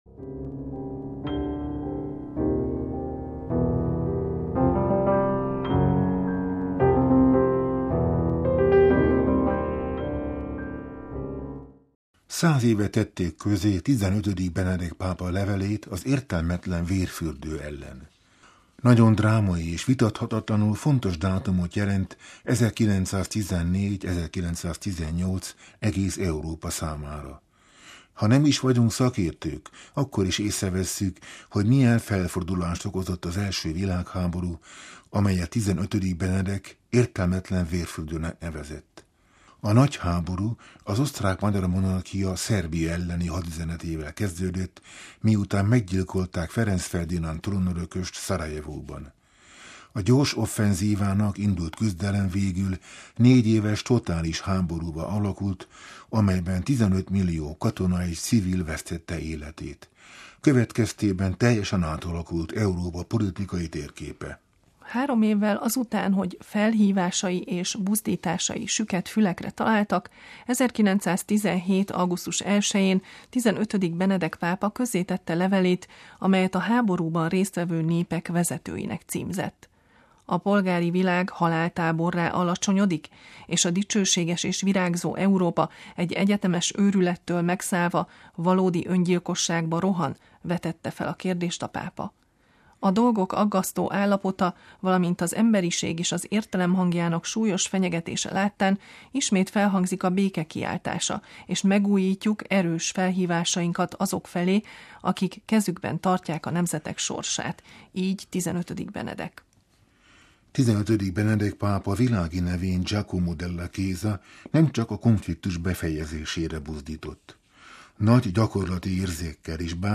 olasz újságíró és történész a Vatikáni Rádiónak adott interjújában